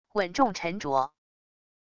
稳重沉着wav音频